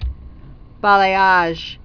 (bälā-äzh)